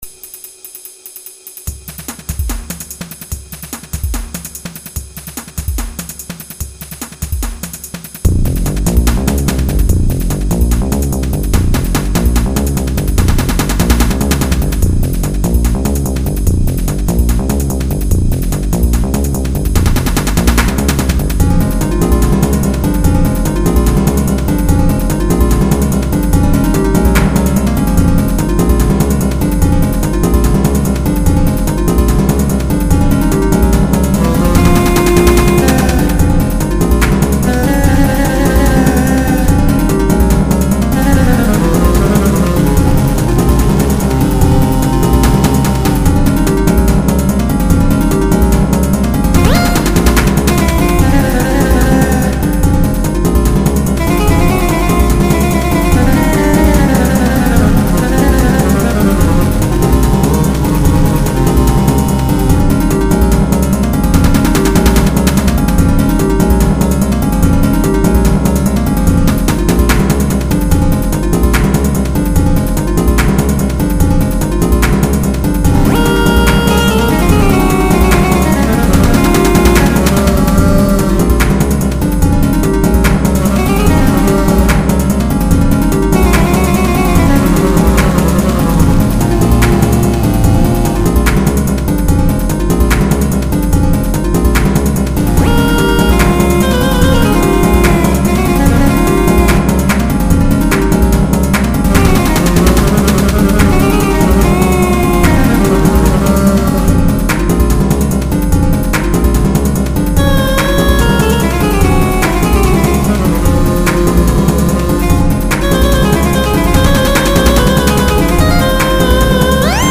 These were all written in music making software.
A competition/dance between clarinet and flute
trying to see how many notes I can cram into a single song.